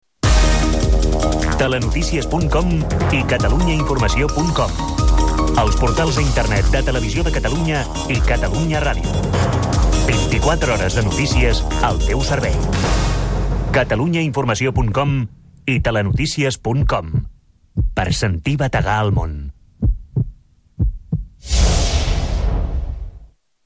Falca de promoció del web de l'emissora i del Telenotícies de TV3.